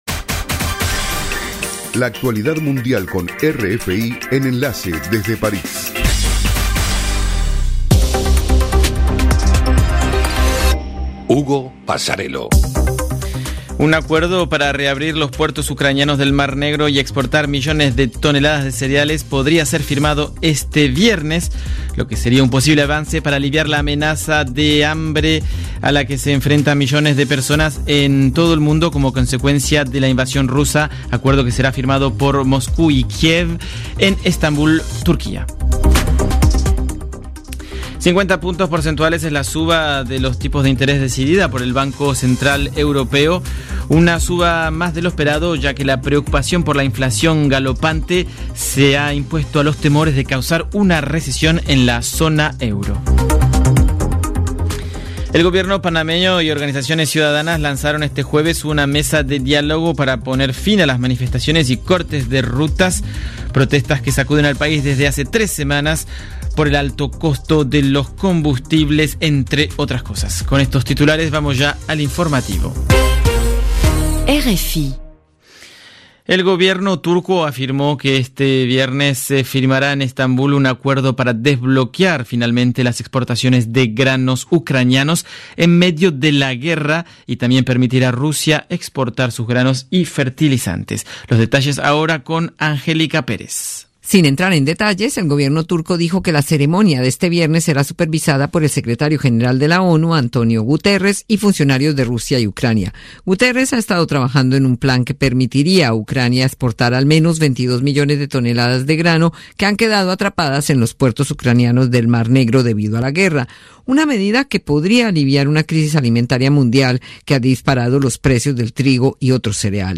Programa: RFI - Noticiero de las 20:00 Hs.